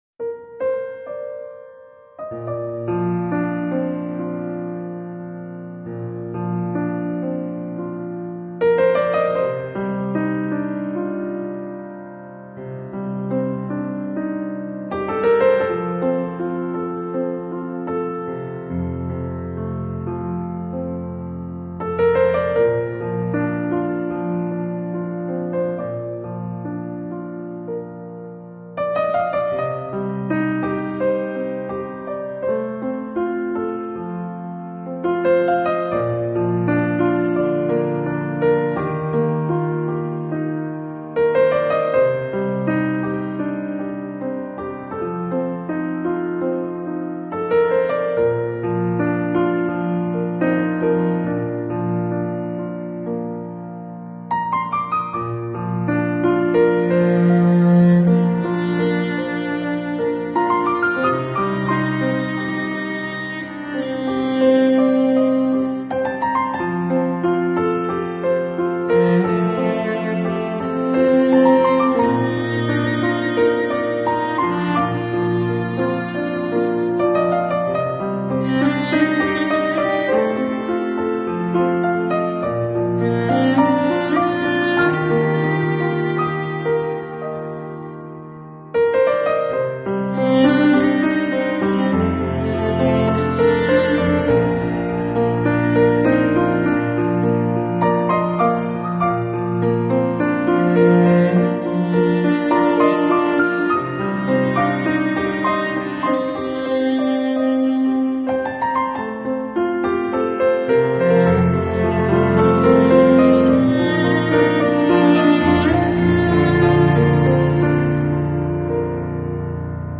类型: New Age / Piano instrumental